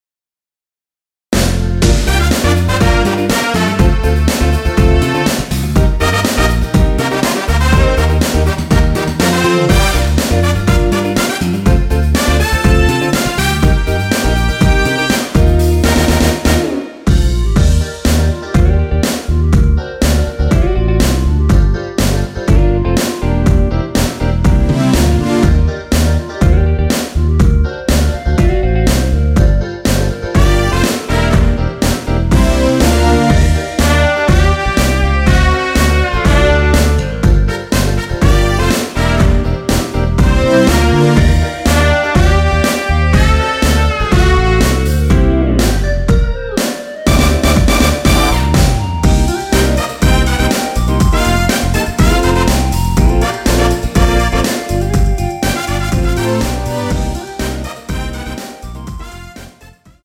원키 멜로디 포함된 MR입니다.
Gm
앞부분30초, 뒷부분30초씩 편집해서 올려 드리고 있습니다.
중간에 음이 끈어지고 다시 나오는 이유는